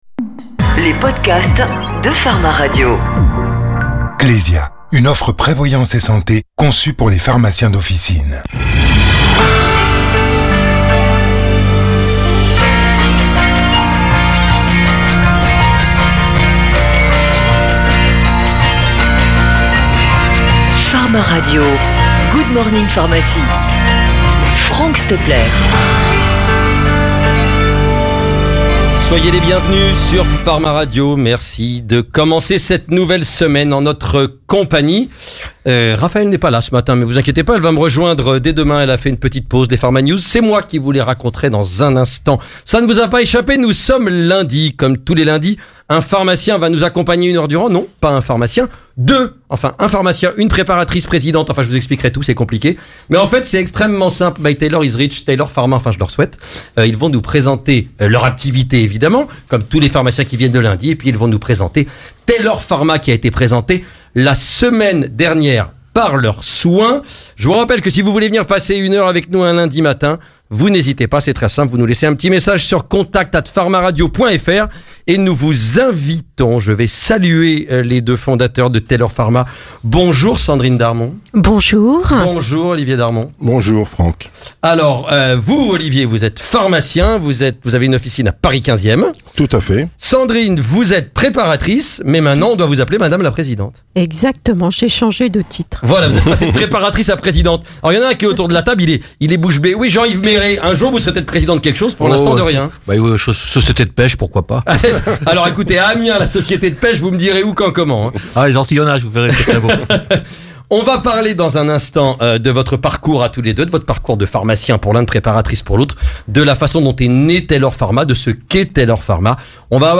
Interview Pharma Radio
Retrouvez notre interview en direct sur :